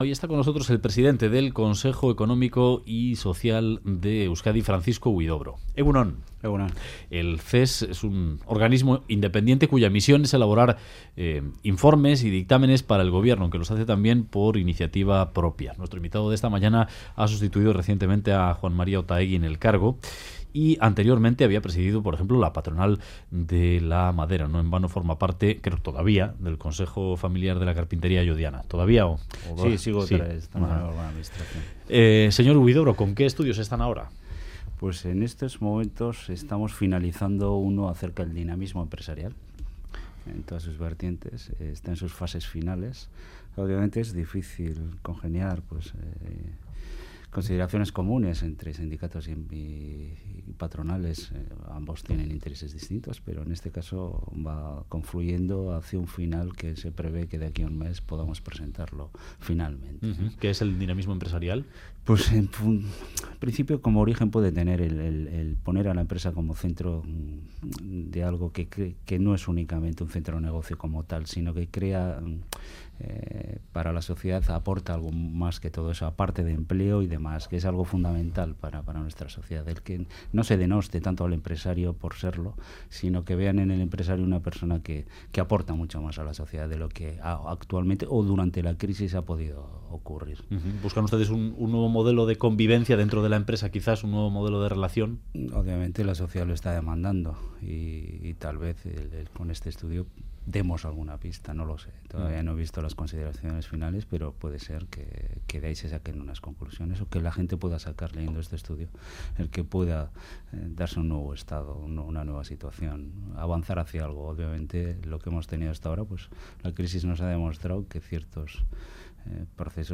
Radio Euskadi BOULEVARD F.Huidobro: 'Judicializar el caso Hiriko no es la mejor solución' Última actualización: 02/03/2015 10:56 (UTC+1) En entrevista al Boulevard de Radio Euskadi, el nuevo presidente del CES-Consejo Económico y Social, Francisco Huidobro, ha afirmado que judicializar casos como el del coche eléctrico "Hiriko" no es la mejor solución, salvo cuando se "enquistan" las cosas. Ha apostado por un cambio en el modelo de empresa y, sobre la ausencia de ELA y LAB del CES, ha subrayado que cuenta con los que están dentro, porque aportan, y ha añadido que el que no está sus razones tendrá.